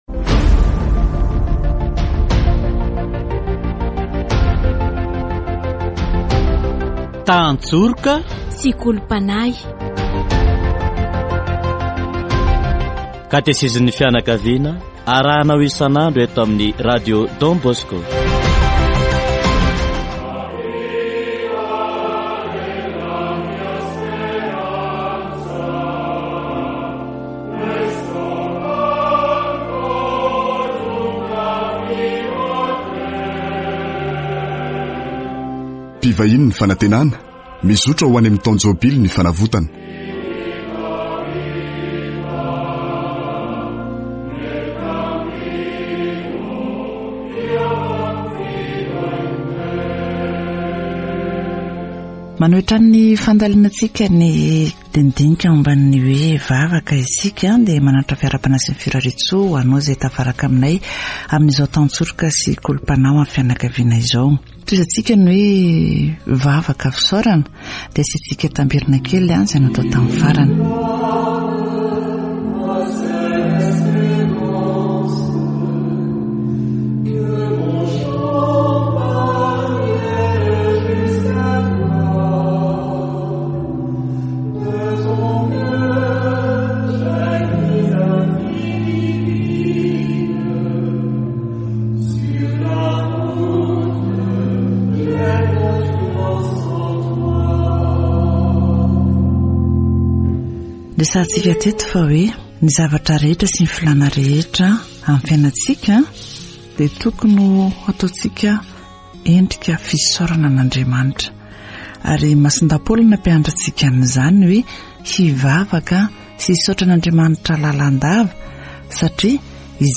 It can be said that thanksgiving to God is the central point of all prayer: persevere in prayer, do it with vigilance and thanksgiving, says Saint Paul in his Letter to the Colossians 4, 2... Catechesis on the Prayer of Thanksgiving